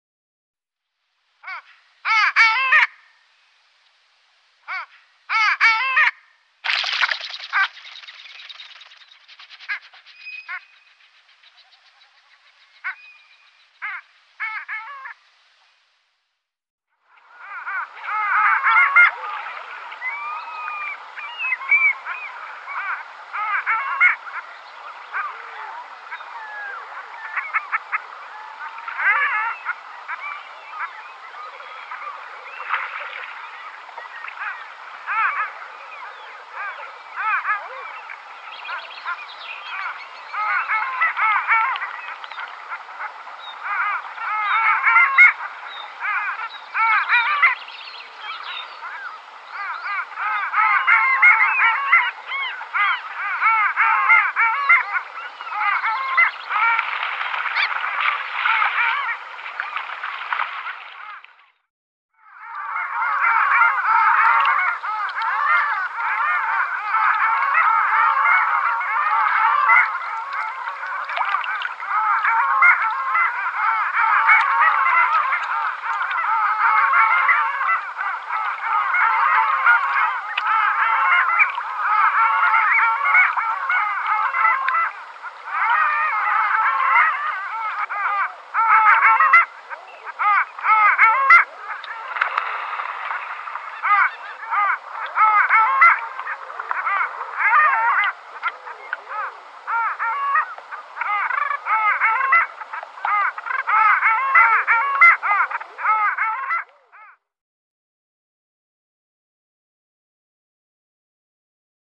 Alfågel / Long-tailed Duck Clangula hyemalis Läte / Sound Du är här > Fåglar / Birds > Alfågel / Long-tailed Duck Galleri med utvalda fågelbilder / Favourites Norge, mars 2025.